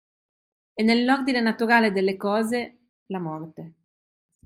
Pronounced as (IPA) /ˈkɔ.ze/